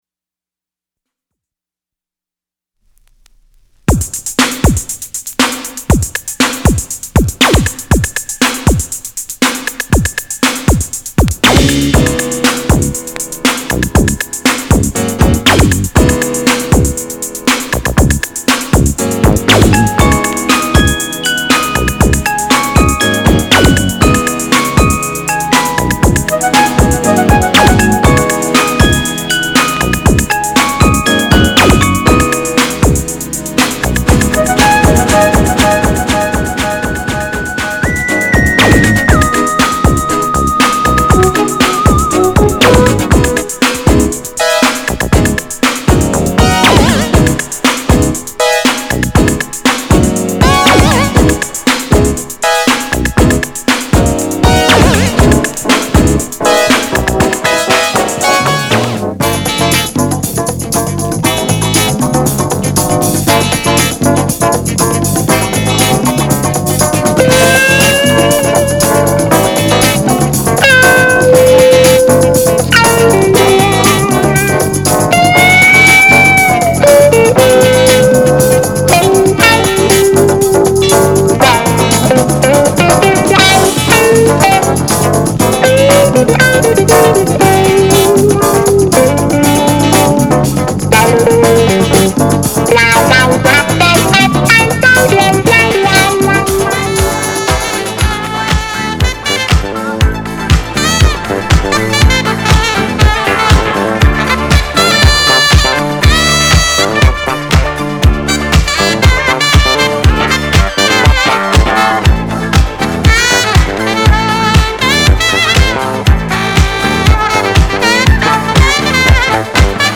類別 Disco